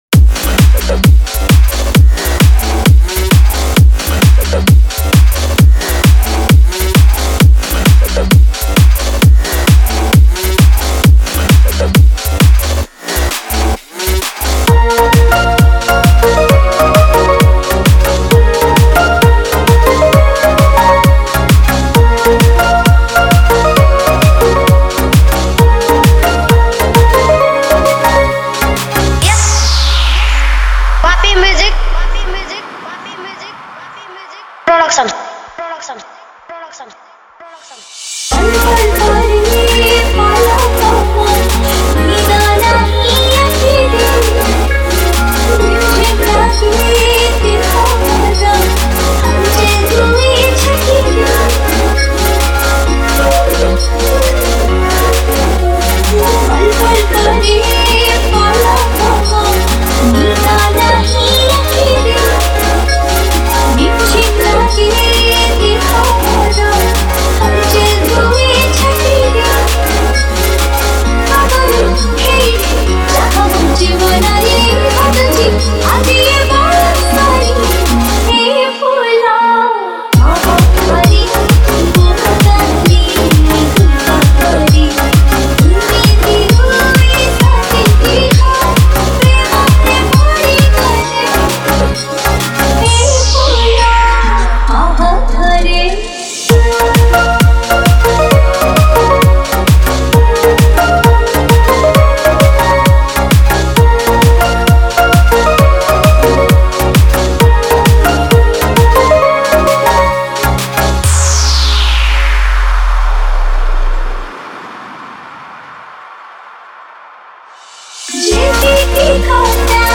New Odia Dj Song 2024